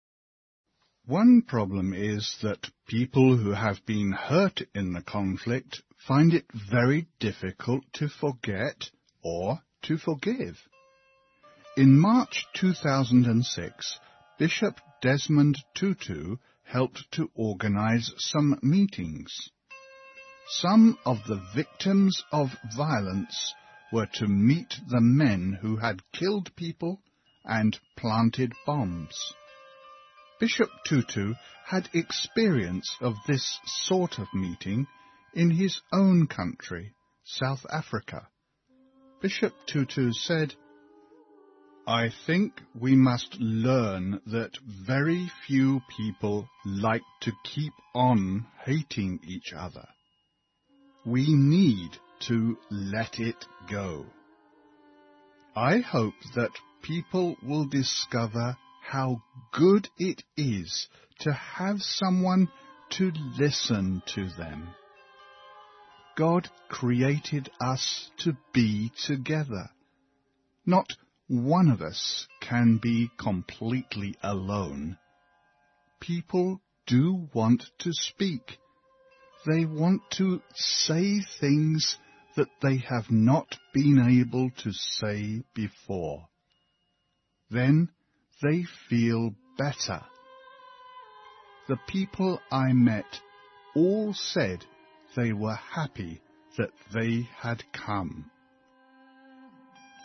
环球慢速英语 第82期:面对真相(3)